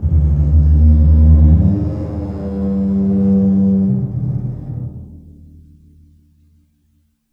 bassdrum_rub4_v1.wav